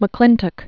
(mə-klĭntək, -tŏk), Barbara 1902-1992.